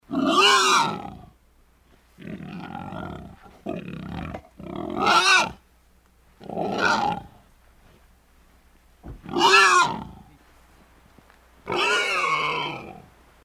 Звуки кабанов
Визг дикого вепря